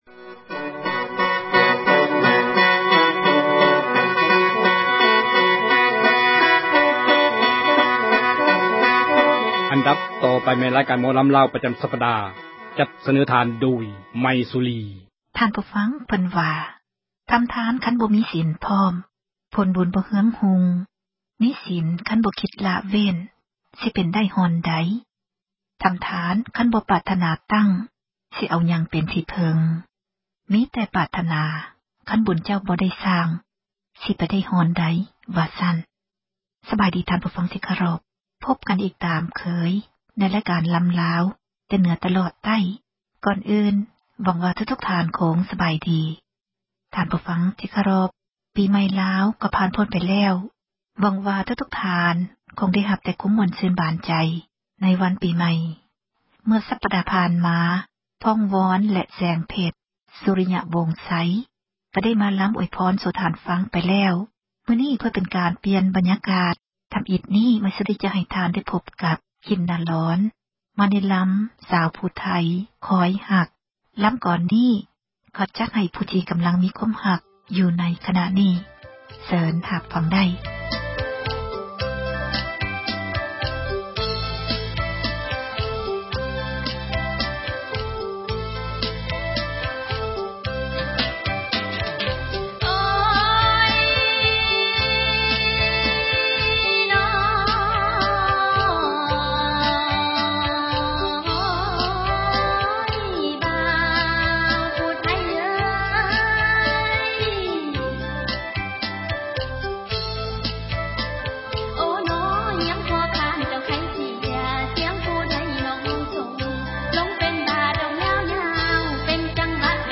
ໝໍລໍາ